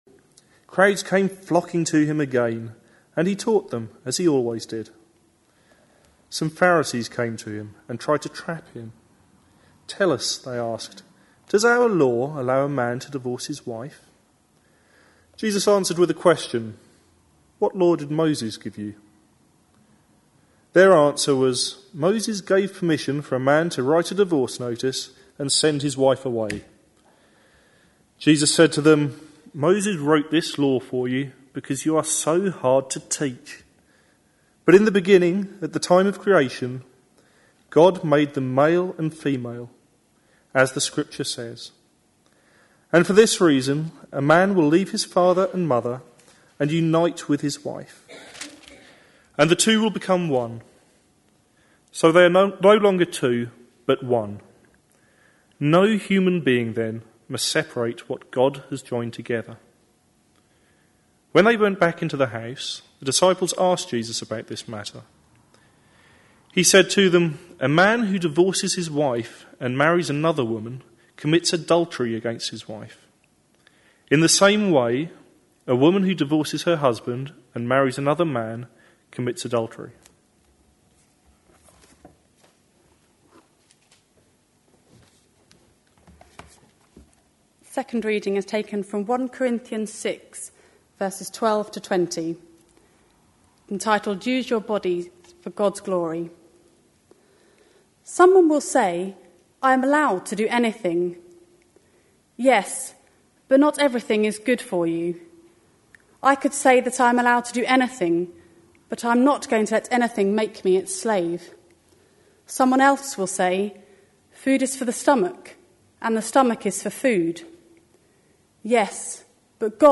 A sermon preached on 2nd May, 2010, as part of our Love Actually series.